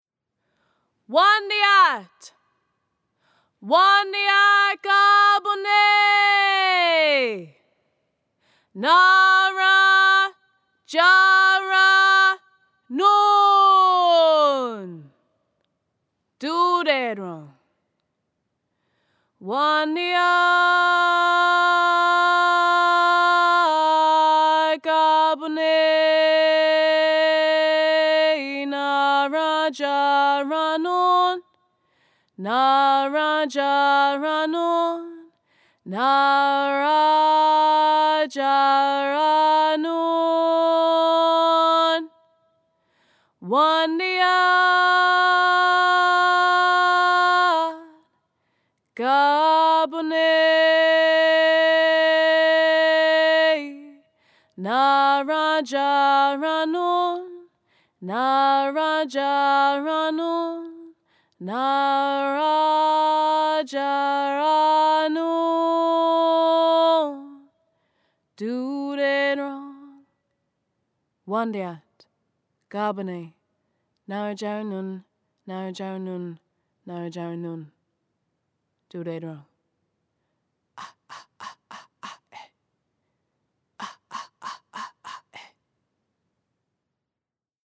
Sung-Take-3.mp3